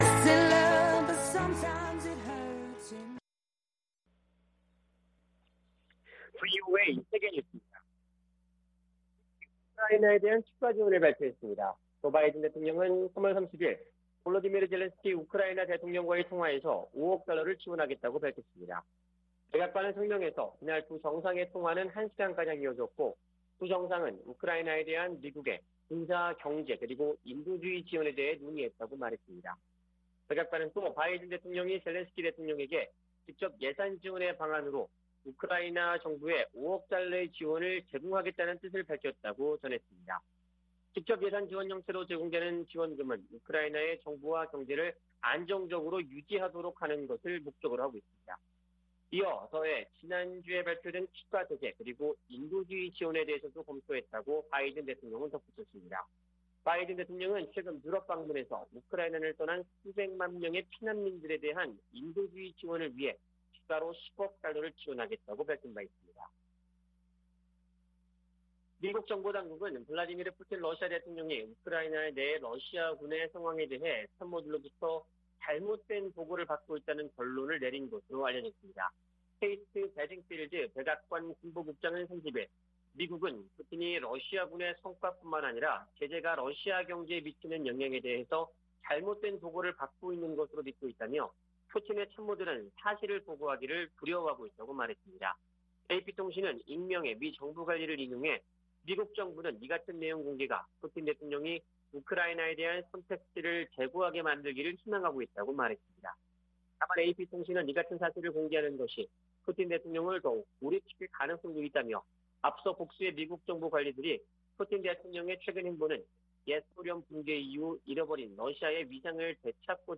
VOA 한국어 아침 뉴스 프로그램 '워싱턴 뉴스 광장' 2022년 4월 1일 방송입니다. 북한이 최근 ICBM을 발사한 곳이 평양 순안공항 인근 미사일 기지에서 멀지 않은 곳으로 확인됐습니다. 북한이 최근 발사한 ICBM이 화성 17형이 아닌 화성 15형이라고 한국 국방부가 밝힌 가운데 미 당국은 여전히 분석 중이라는 입장을 내놨습니다. 북한에서 6개월 안에 식량상황 악화 등 인도주의적 위기가 발생할 가능성이 있다고 스위스 비정부기구가 전망했습니다.